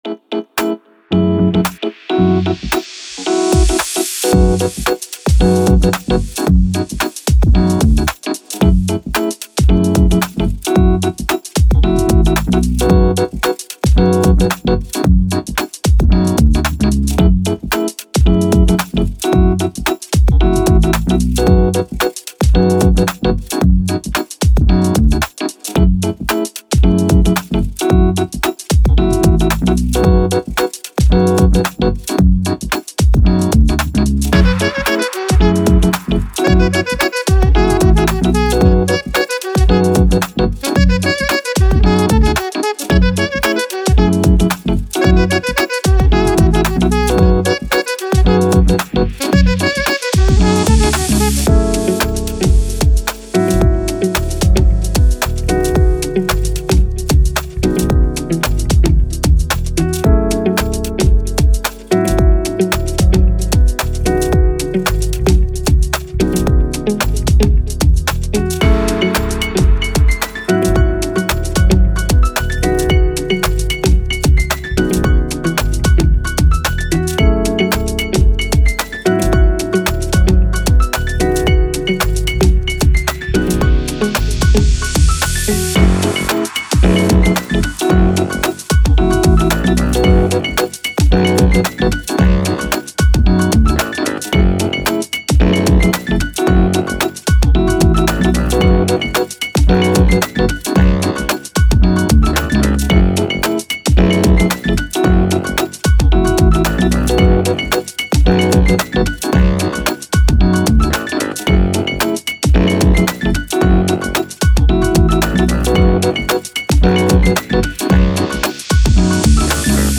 Dance, Breakbeat, Chill, Positive